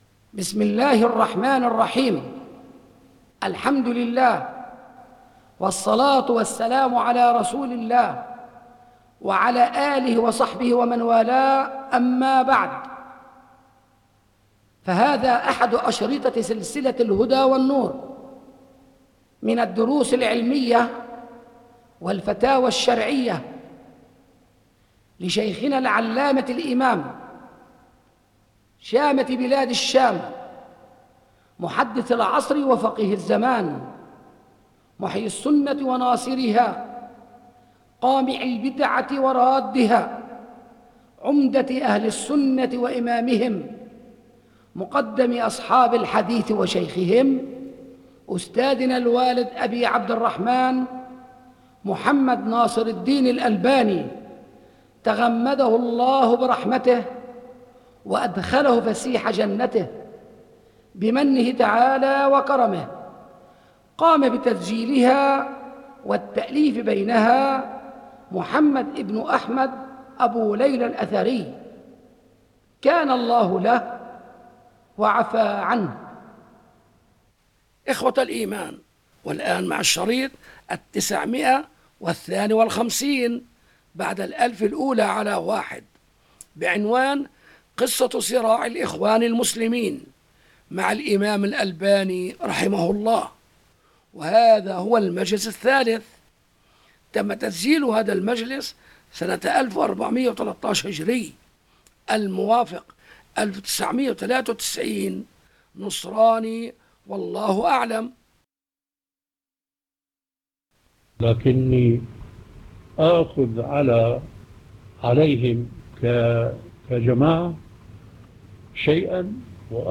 بِصوتِ الإِمامِ الألبَانِي